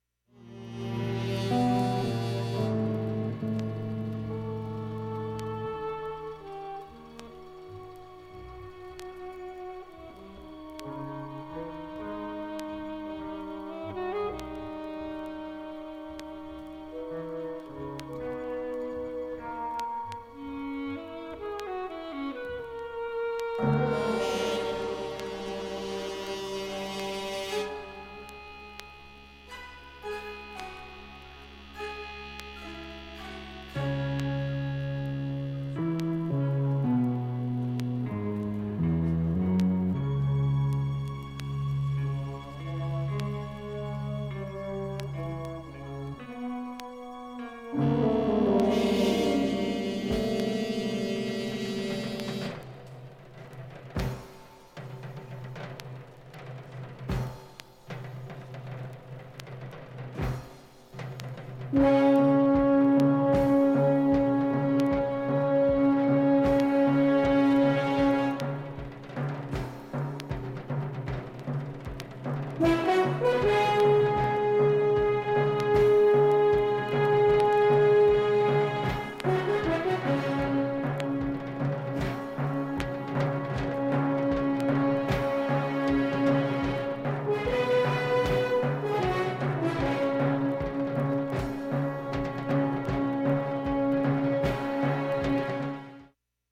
ほかスレプツなど聴こえてくるものは
現物の試聴（上記）できます。音質目安にどうぞ